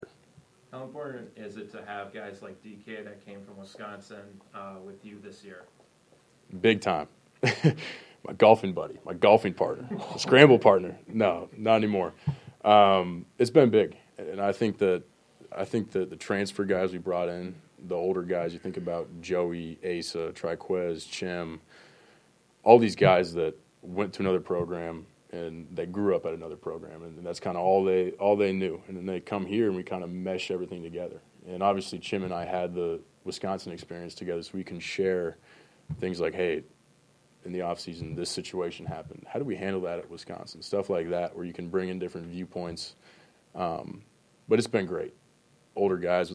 Florida quarterback Graham Mertz previewed a new season of Gator football and the opening matchup against No. 19 Miami in a news conference Monday.